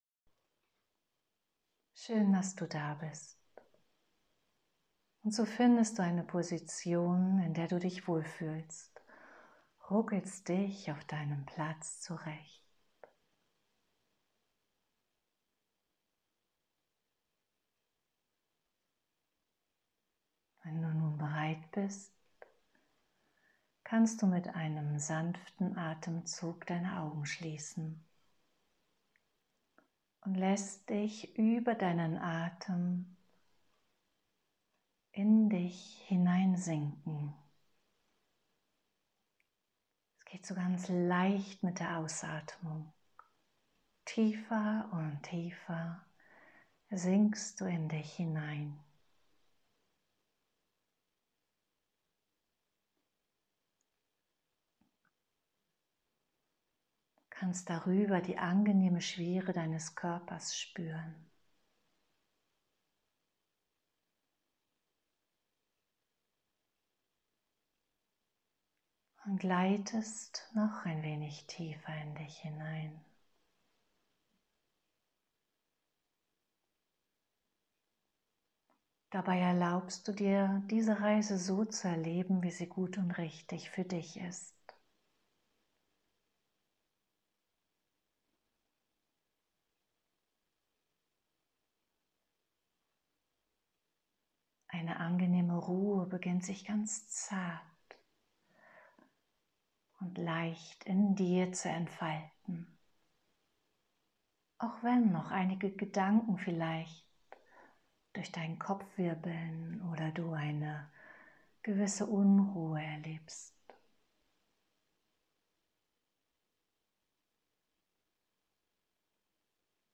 Du findest hier unterschiedliche Meditationsformen, die einfach und klar strukturiert sowie freilassend sind.
Wichtig war mir auch, dass die geführten Meditationen über ihre relative Kürze gut in den normalen Alltag zu integrieren sind.